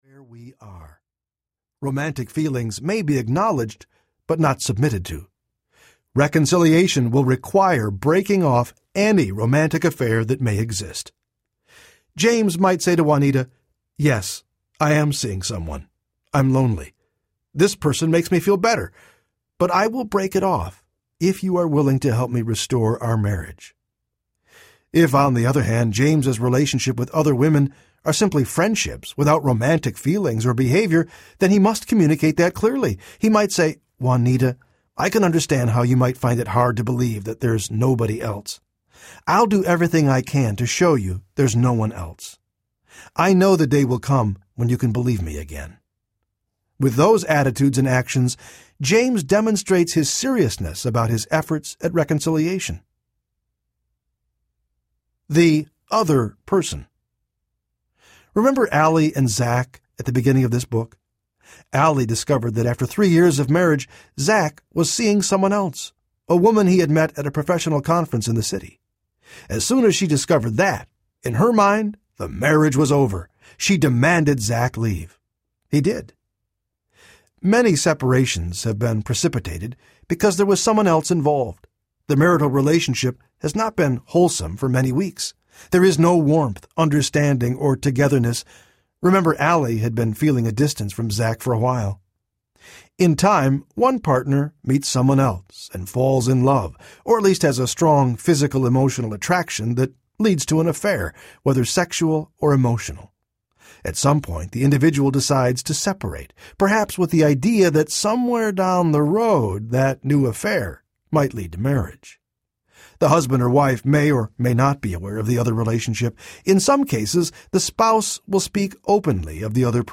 One More Try Audiobook
3.4 Hrs. – Unabridged